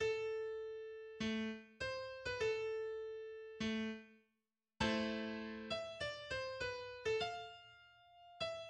Allegro energico, ma non troppo
C’est une marche sombre et menaçante, scandé par un instrument à percussion emprunté aux musiques militaire : la caisse claire[6].